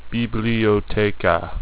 This is because of the dead space that is inherent at the start and end of file, due to the delay between recording beginning and the speech sample starting (and similarly at the end).